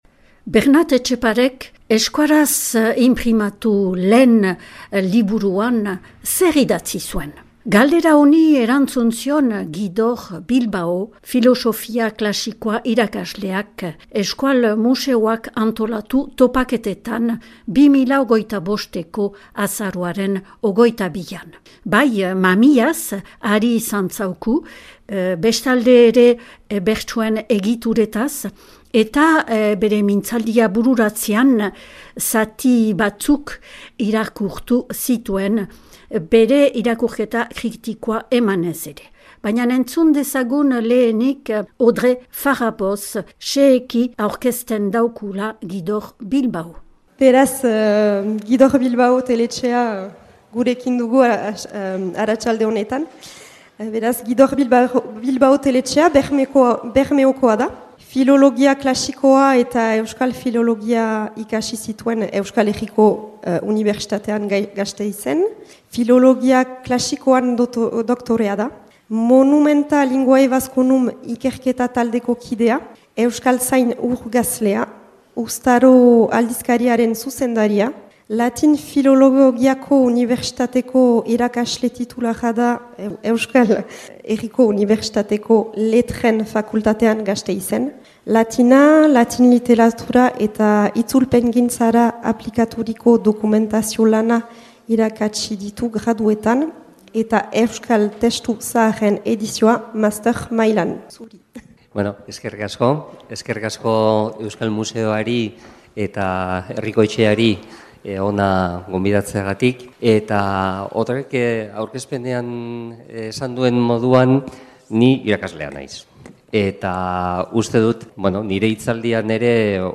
Euskaraz inprimatu lehen liburuaren inguruko topaketak, Euskal museoak antolaturik 2025eko azaroaren 22an Baionako Herriko Etxean.